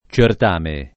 ©ert#me] s. m. — anche certamen [lat. ©ert#men], pure s. m. (in it.), come titolo (completato da qualche agg.) di competizioni letterarie di latinisti moderni; pl. certamina [©ert#mina] — cfr. coronario; singolare